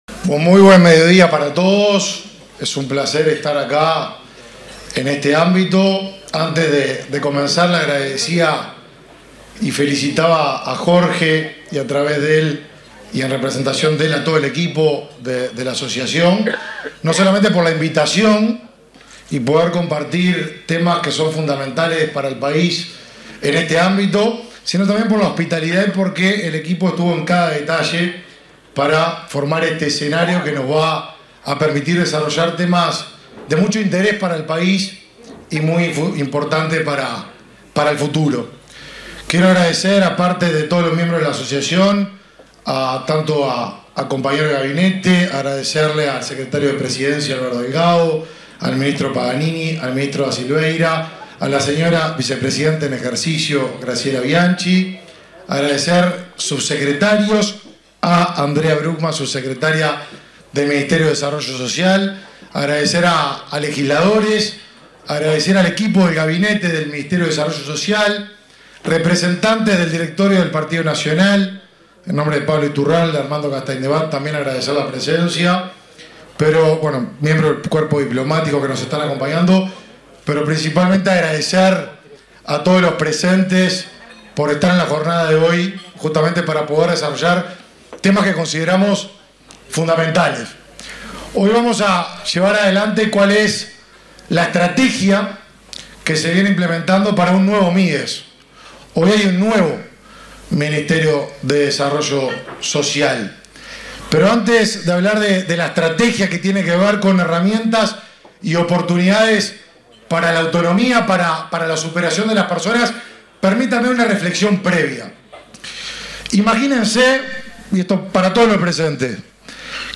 Palabras del ministro de Desarrollo Social, Martín Lema
Palabras del ministro de Desarrollo Social, Martín Lema 13/09/2023 Compartir Facebook X Copiar enlace WhatsApp LinkedIn En el marco de un almuerzo de trabajo organizado por la Asociación de Dirigentes de Marketing (ADM), este 13 de setiembre, se expresó el ministro de Desarrollo Social, Martín Lema.